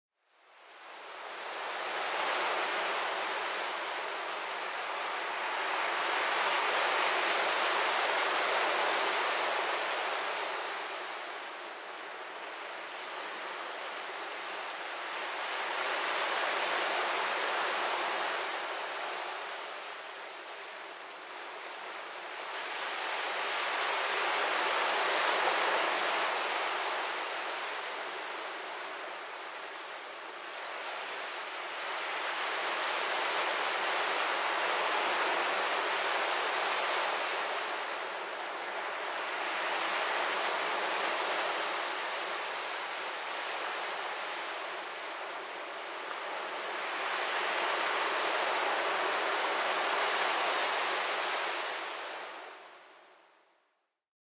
freesound_community-seashore-60589.mp3